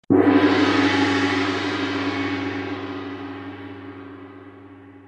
Другие рингтоны по запросу: | Теги: Гонг
Категория: Различные звуковые реалтоны